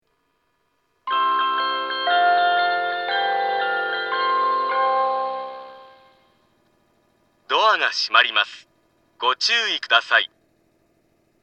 発車メロディー
一度扱えばフルコーラス鳴ります。
スピーカーがユニペックスマリンなので音質が悪いです。